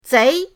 zei2.mp3